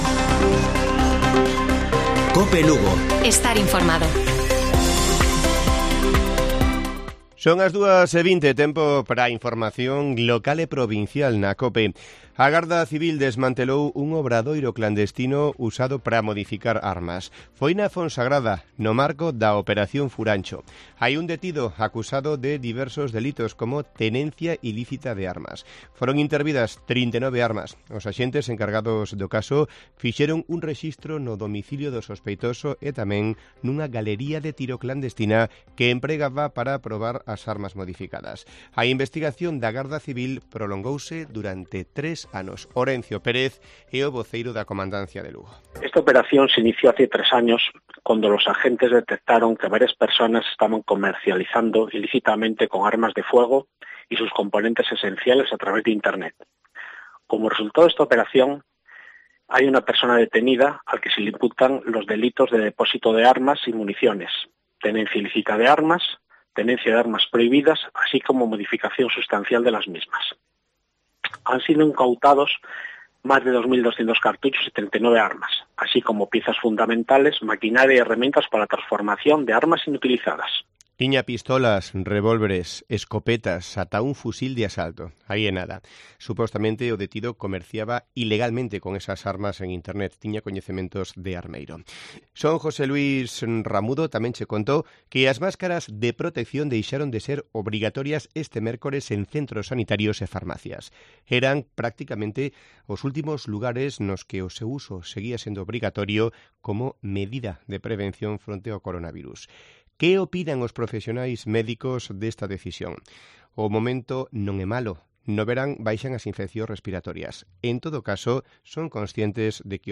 Informativo Mediodía de Cope Lugo. 5 DE JULIO. 14:20 horas